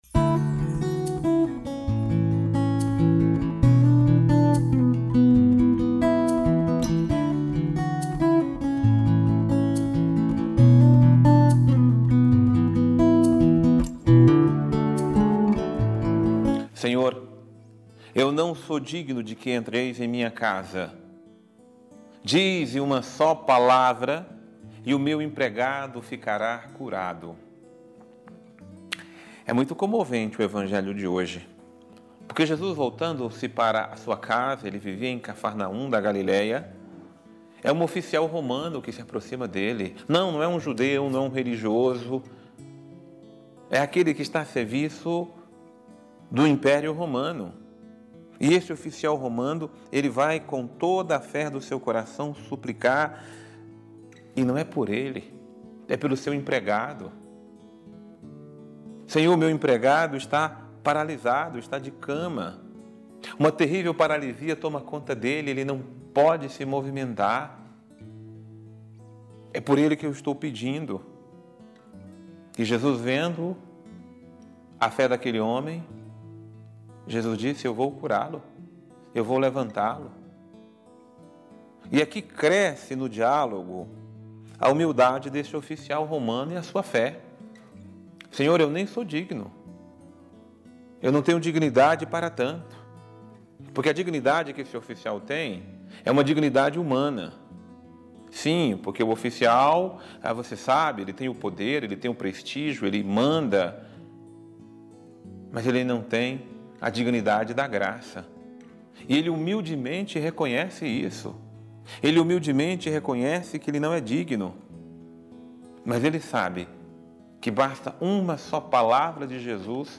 Homilia | Somente os humildes possuem a fé autêntica